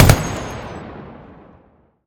gun-turret-shot-1.ogg